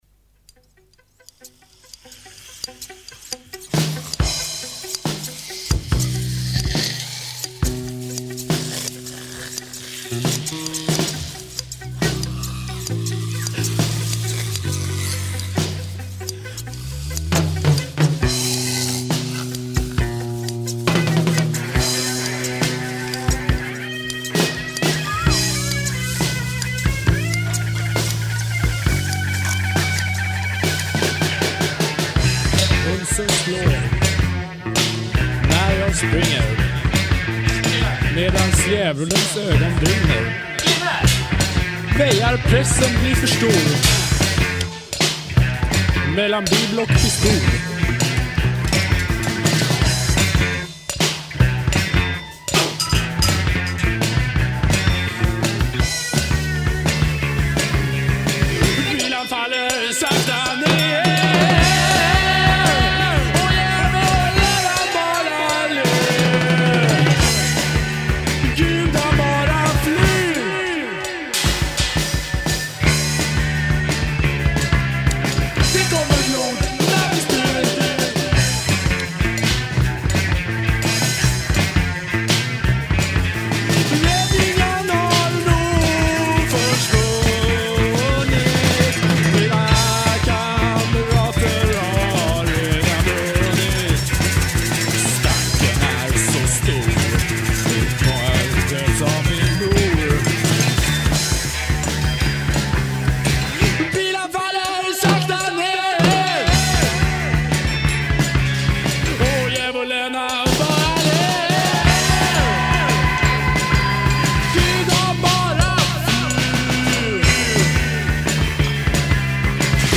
Bass, b-Stimme
Gitarre, b-Stimme
Trommel